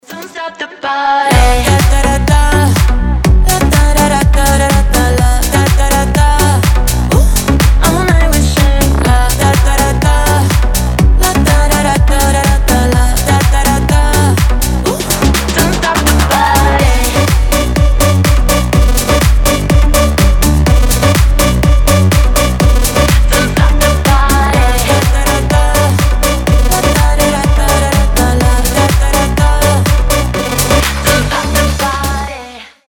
зажигательные
house